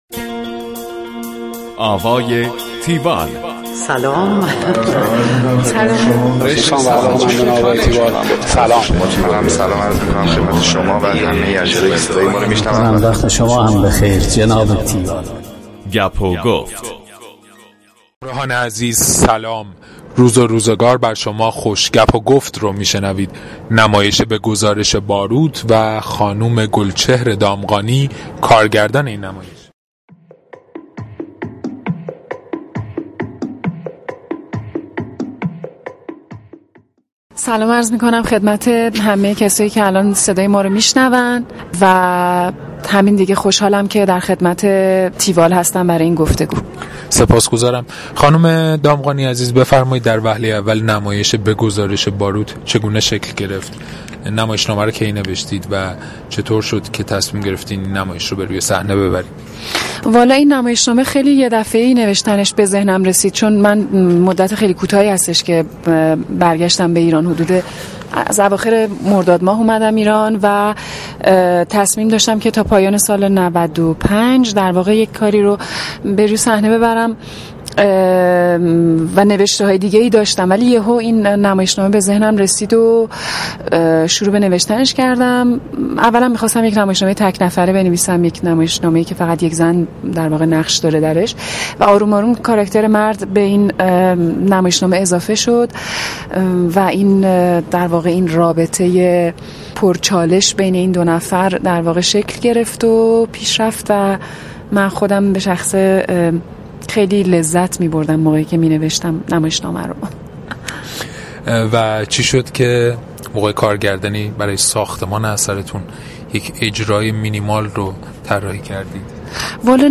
گفتگوی تیوال
نویسنده و کارگردان.